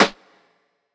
Medicated Snare 9.wav